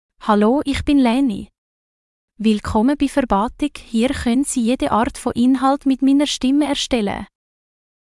FemaleGerman (Switzerland)
Leni — Female German AI voice
Voice sample
Listen to Leni's female German voice.
Female
Leni delivers clear pronunciation with authentic Switzerland German intonation, making your content sound professionally produced.